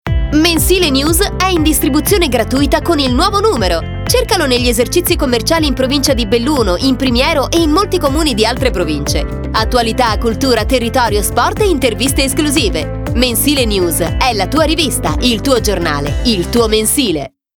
LO SPOT ALLA RADIO